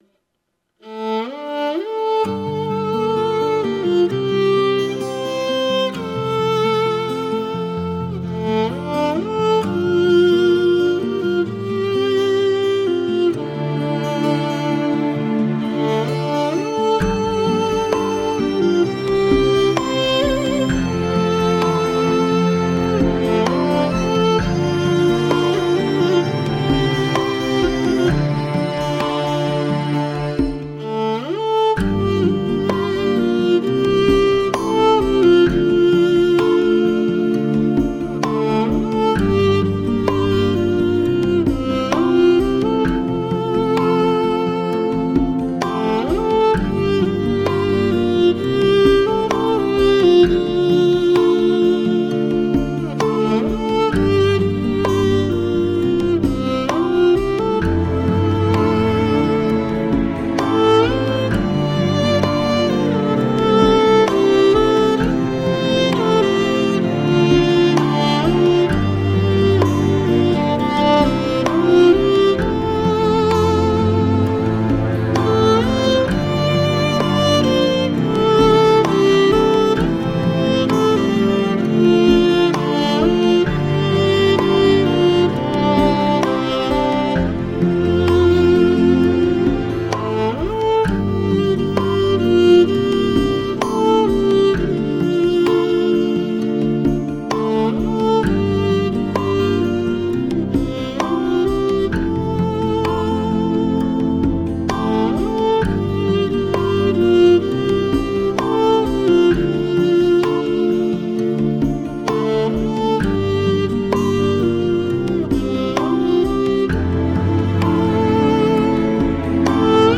弦音袅袅 琴声悠扬 在跌宕起伏的旋律里诉说衷肠
马头琴是蒙古民族最具有代表性的乐器，其音色纯朴、浑厚、极富感染力，这也是只有在草原上才能听到的声音。